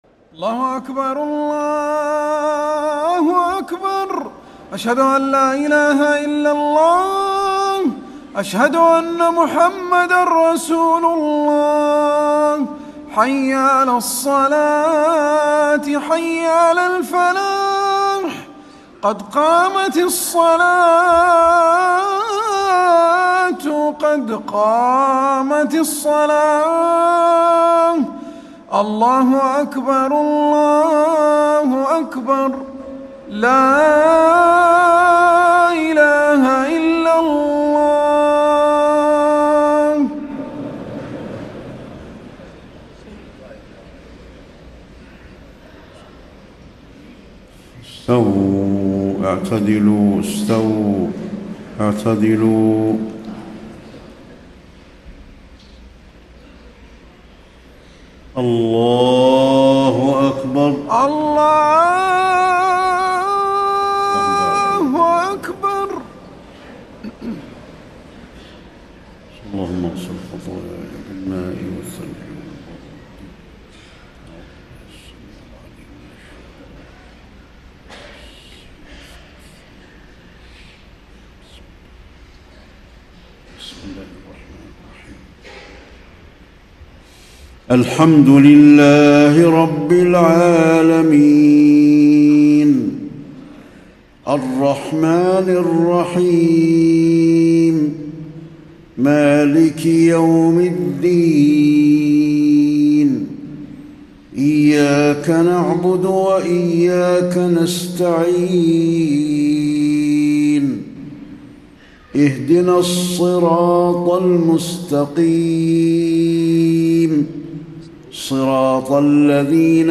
صلاة العشاء 4-5-1434 من سورة مريم > 1434 🕌 > الفروض - تلاوات الحرمين